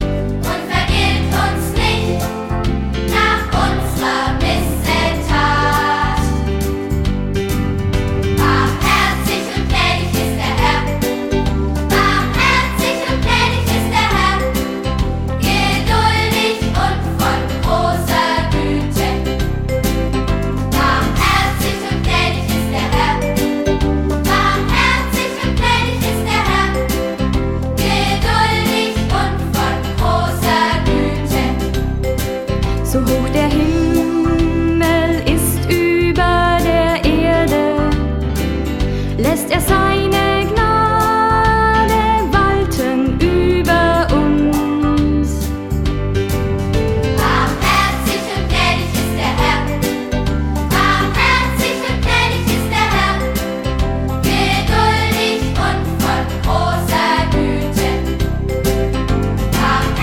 Kinderlieder 0,99 € 2.